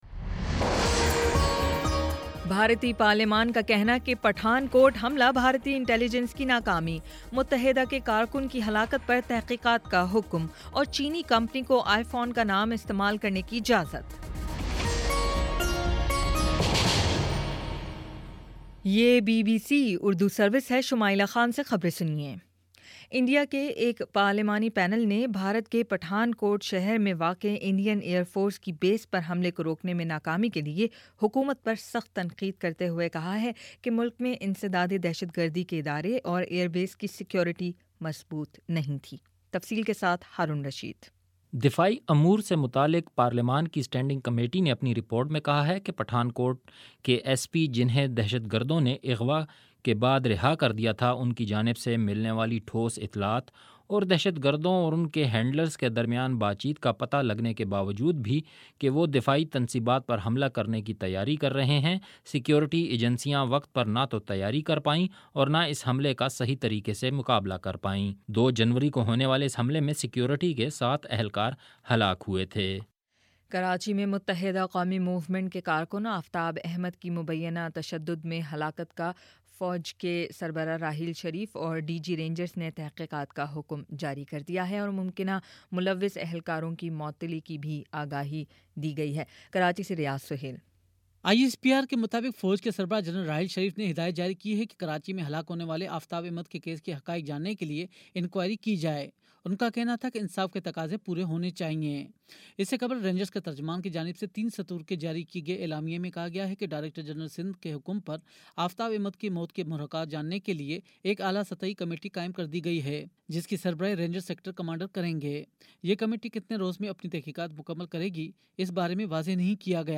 مئی 04 : شام پانچ بجے کا نیوز بُلیٹن